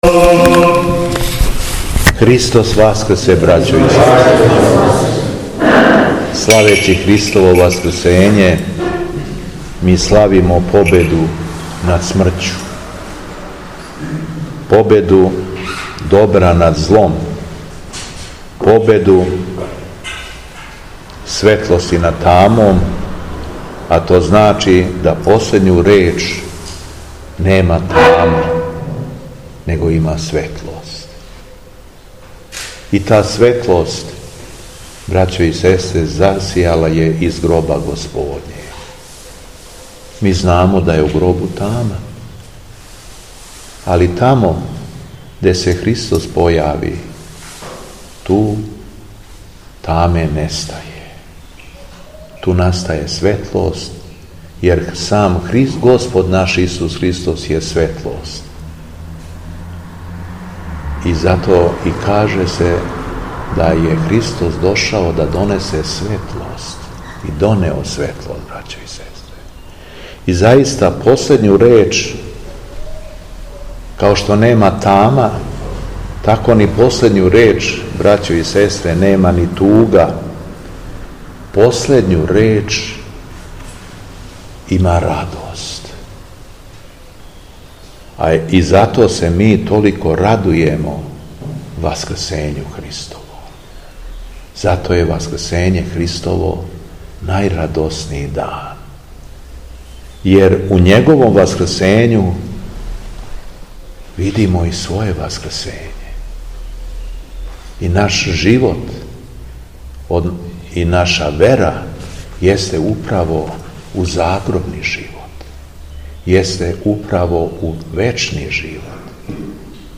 Беседа Његовог Високопреосвештенства Митрополита шумадијског г. Јована
Након прочитаног јеванђеља Митрополит се обратио сакупљеном народу: